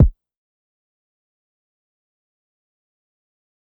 SizzKick3.wav